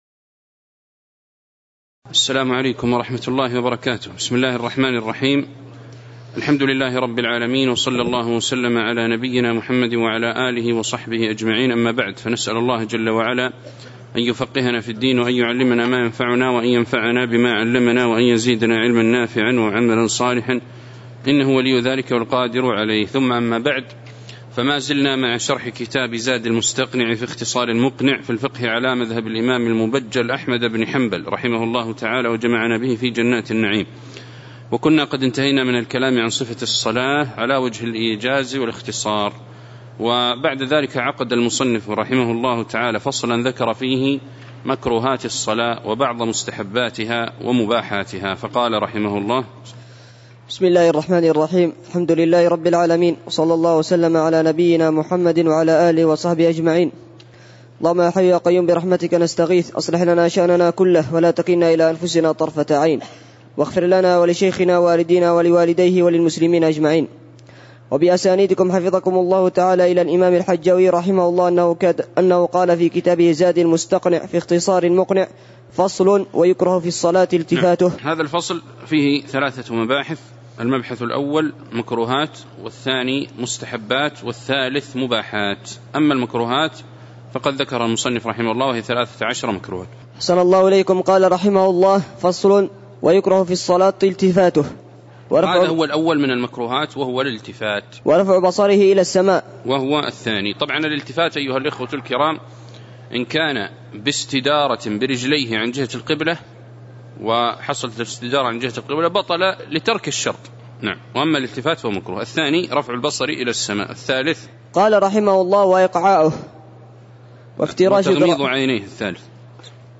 تاريخ النشر ٢٩ محرم ١٤٤٠ هـ المكان: المسجد النبوي الشيخ